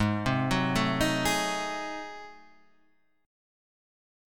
G#7b9 chord {4 3 4 2 4 4} chord